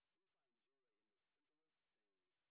sp07_white_snr20.wav